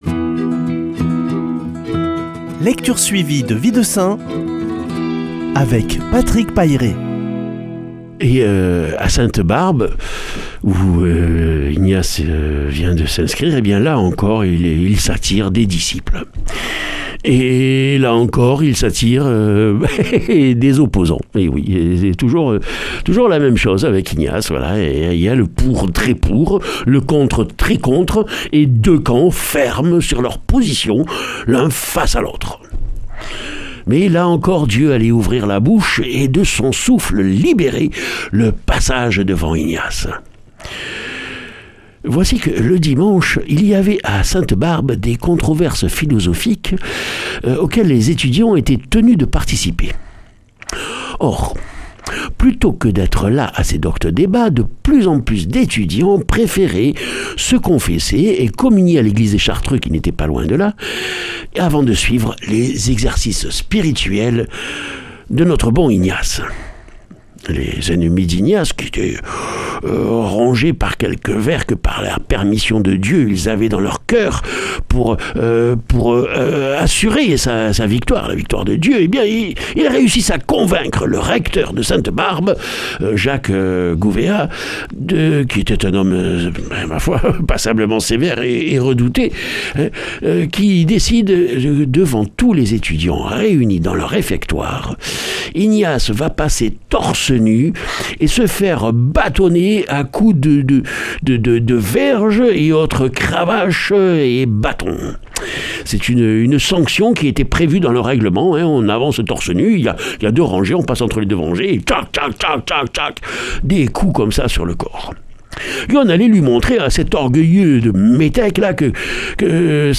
vendredi 1er mai 2020 Récit de vie de saints Durée 2 min
Lecture suivie de la vie des saints